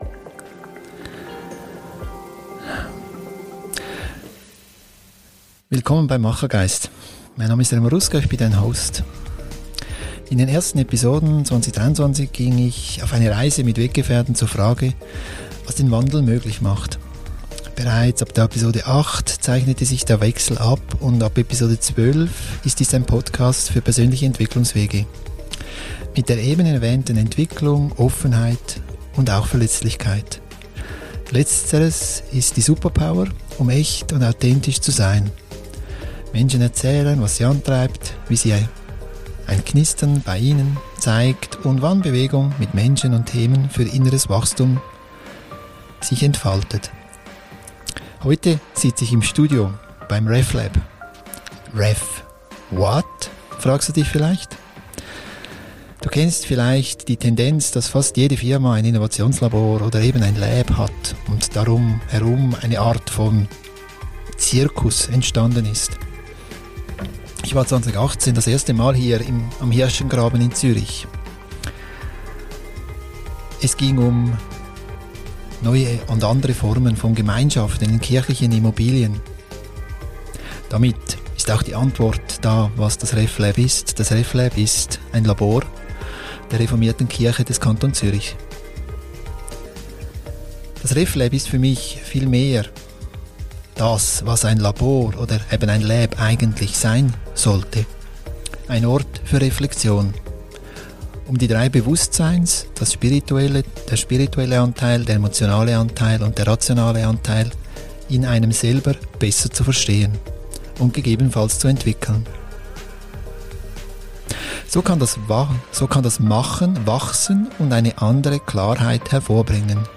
Ein Gespräch zum Hören, Reflektieren, Abtauchen und Nachdenken.